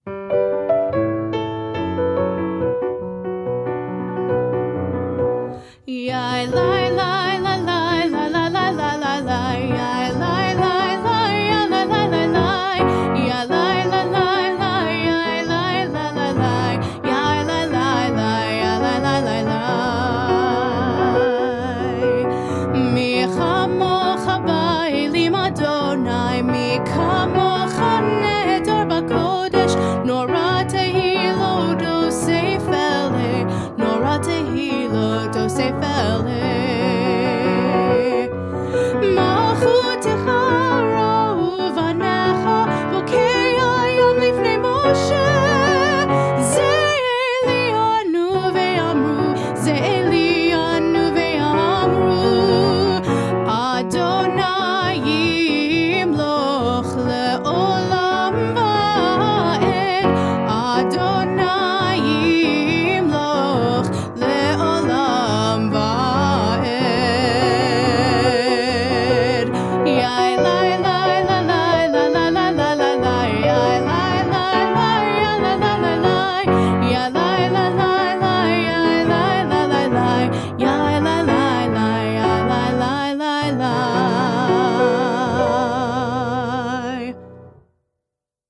We read in English the blessing for redemption, and then sing